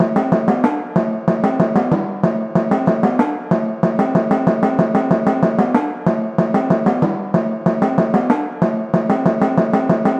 砰A地段打击乐
Tag: 94 bpm Hip Hop Loops Percussion Loops 1.72 MB wav Key : Unknown